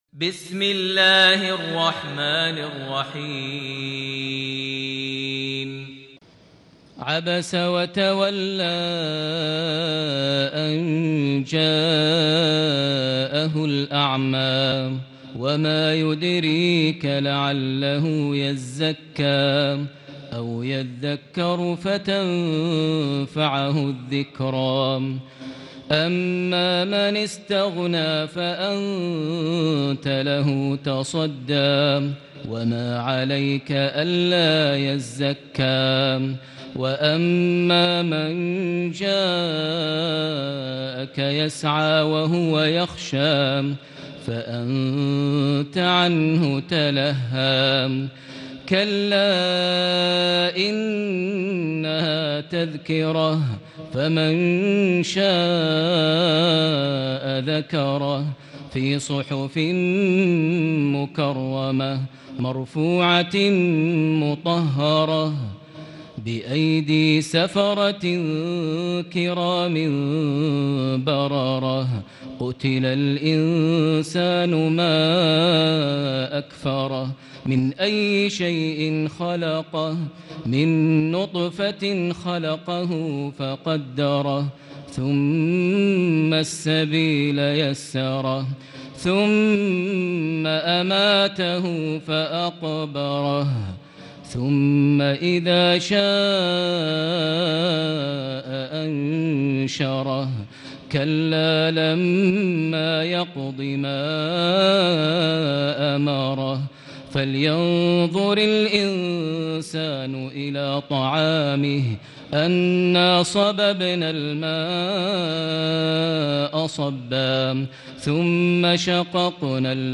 سورة عبس > مصحف الشيخ ماهر المعيقلي (2) > المصحف - تلاوات ماهر المعيقلي